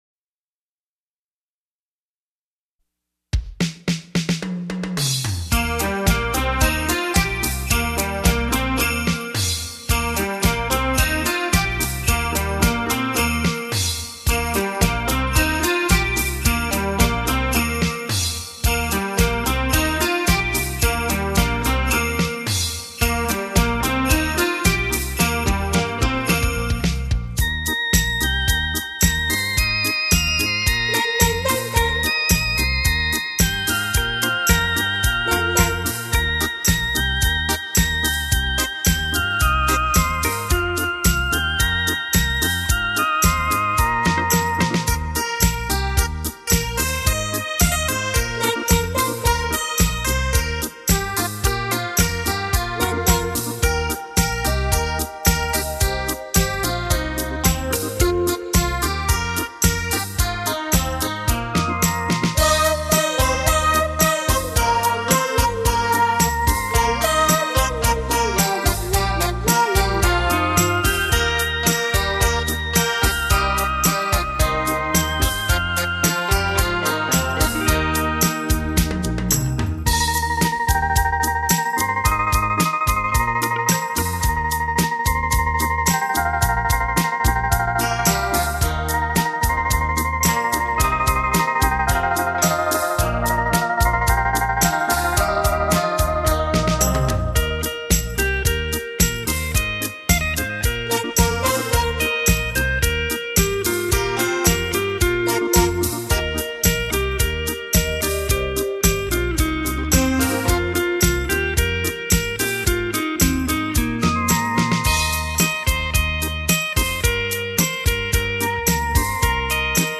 清新典雅的韵味、超凡脱俗的享受，
经典的歌曲，全新的演译，